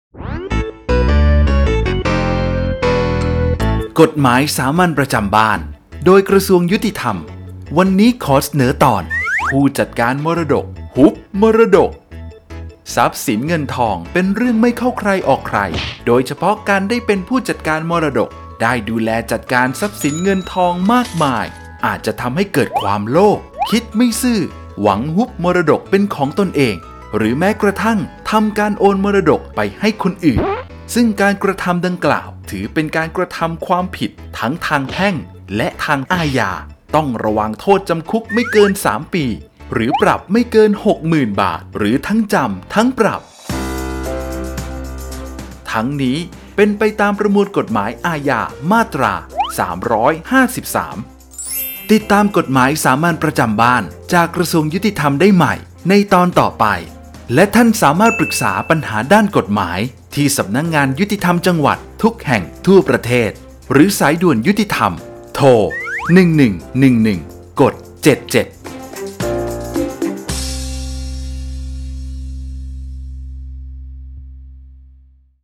ชื่อเรื่อง : กฎหมายสามัญประจำบ้าน ฉบับภาษาท้องถิ่น ภาคกลาง ตอนผู้จัดการมรดก ฮุบมรดก
ลักษณะของสื่อ :   บรรยาย, คลิปเสียง